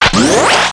assets/nx/nzportable/nzp/sounds/weapons/raygun/close.wav at af6a1cec16f054ad217f880900abdacf93c7e011